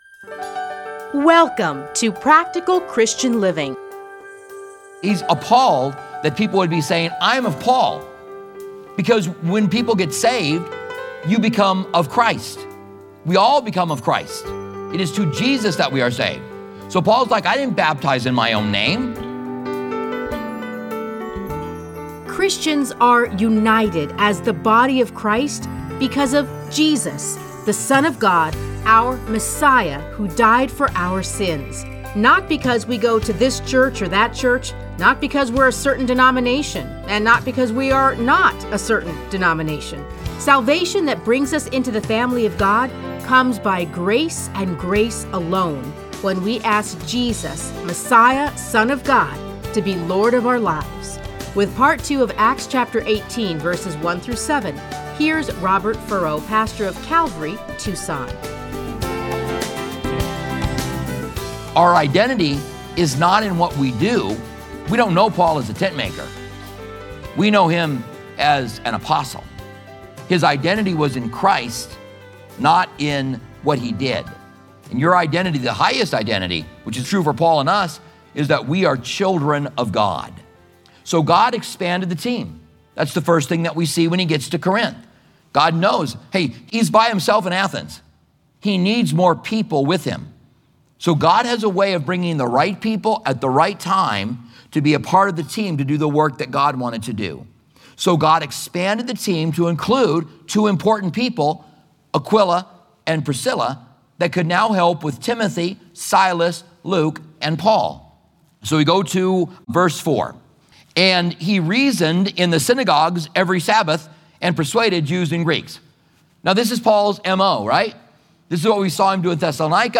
Listen to a teaching from Acts 18:1-17.